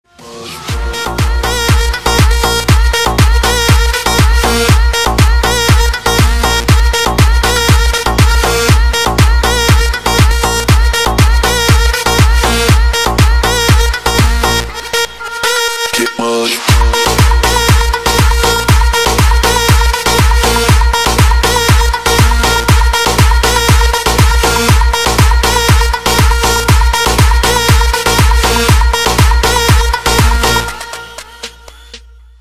• Качество: 320, Stereo
громкие
house
балканские
Club Dance